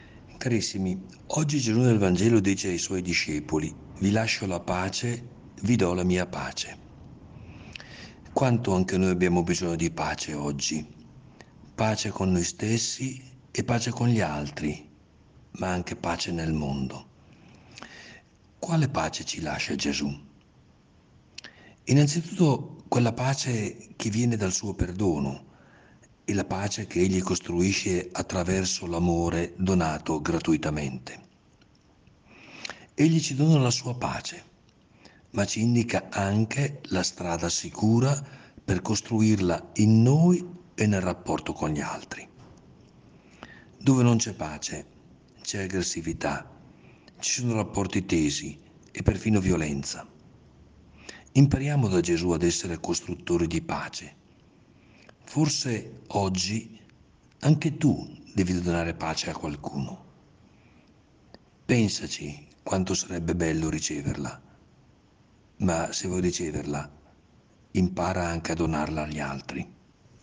Il Vescovo commenta la Parola di Dio per trarne ispirazione per la giornata.